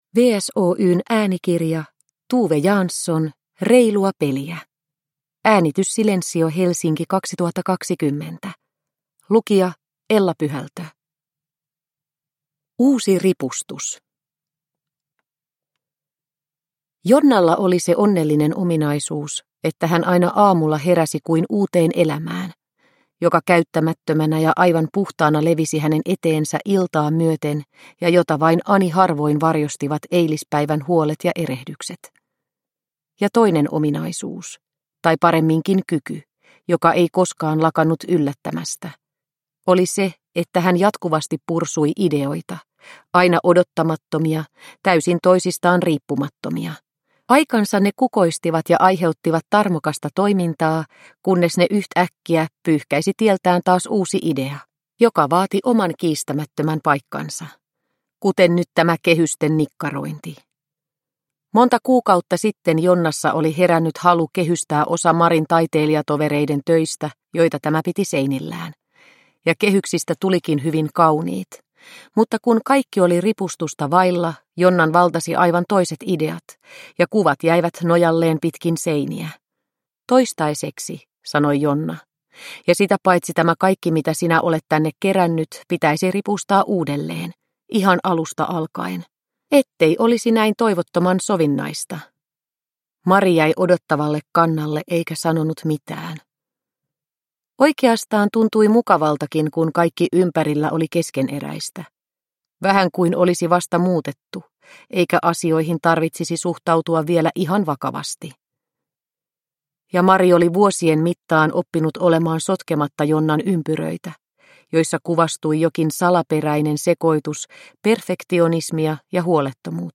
Reilua peliä – Ljudbok – Laddas ner